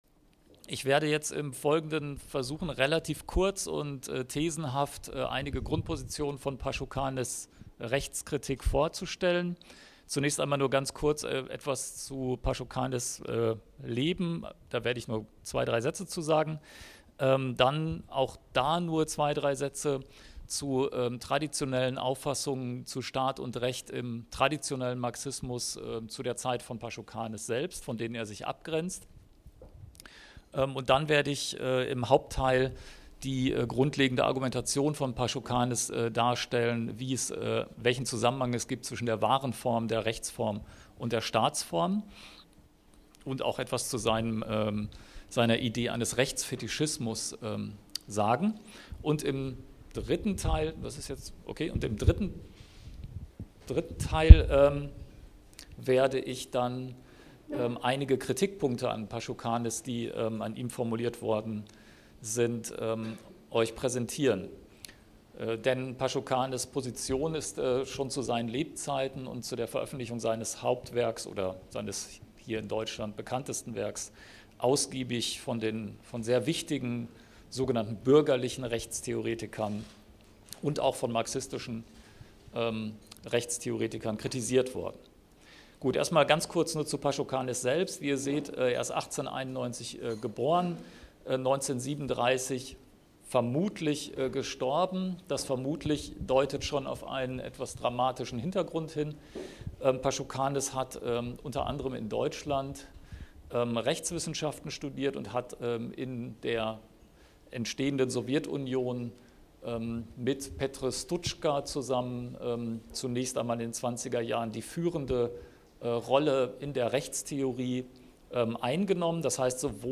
(Vortrag vom 17.1.2018, Café KOZ, Frankfurt/M.)